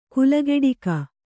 ♪ kulageḍika